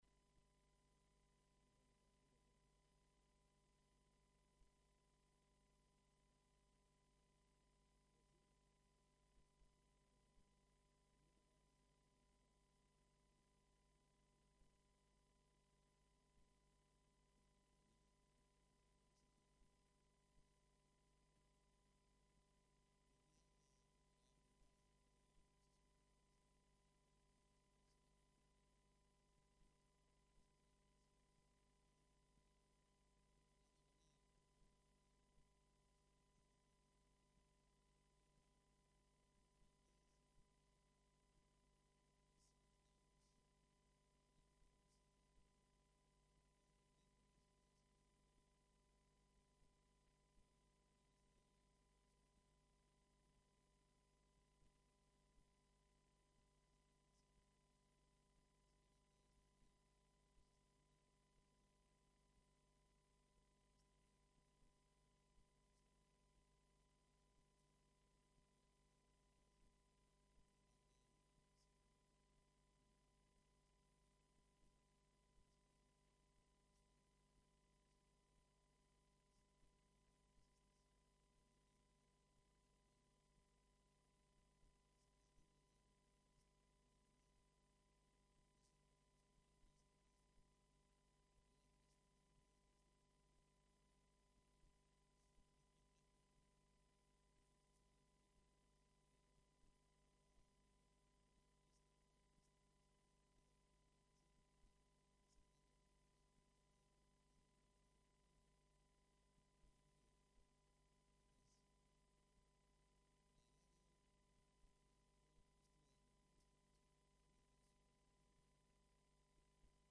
DESCÁRGATE la predicación aquí: Estudio-sobre-el-juicio